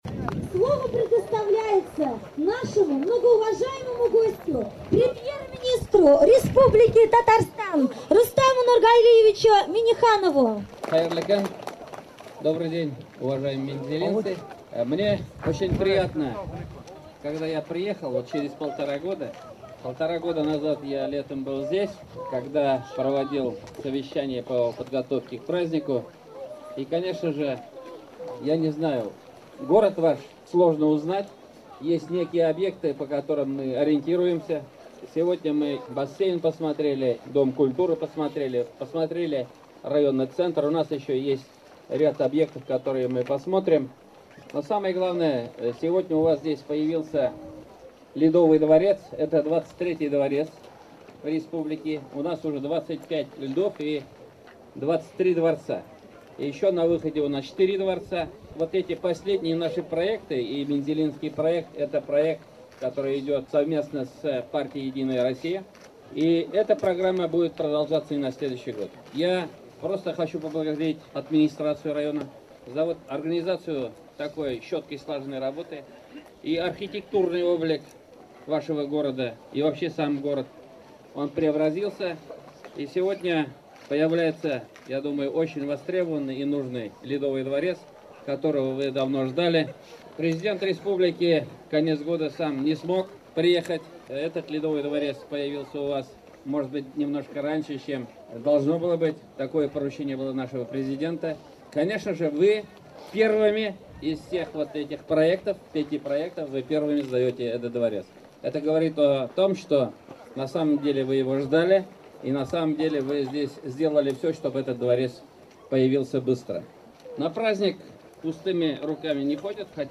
Торжественное открытие Ледового дворца в г.Мензелинске с участием Премьер-министра Республики Татарстан Р.Н.Минниханова
Выступление Премьер-министра Республики Татарстан Р.Н.Минниханова перед открытием Ледового дворца в Мензелинске